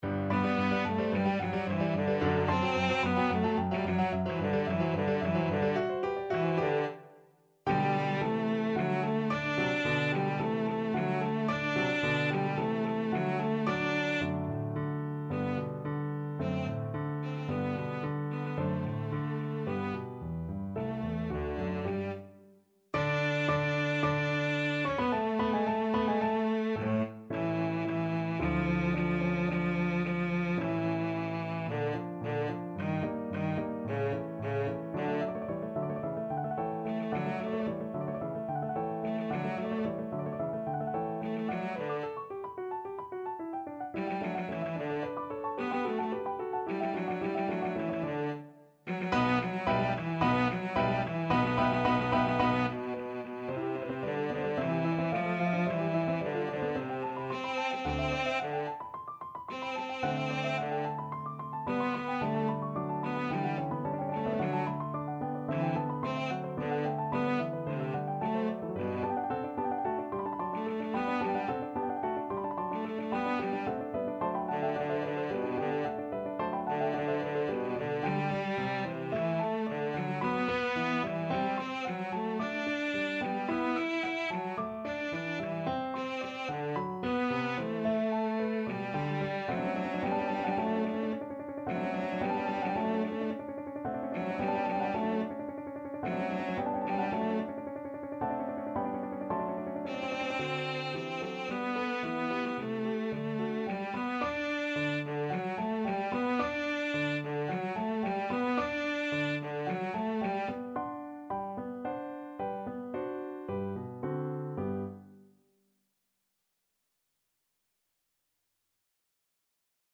Cello
4/4 (View more 4/4 Music)
Allegro assai =220 (View more music marked Allegro)
G major (Sounding Pitch) (View more G major Music for Cello )
Classical (View more Classical Cello Music)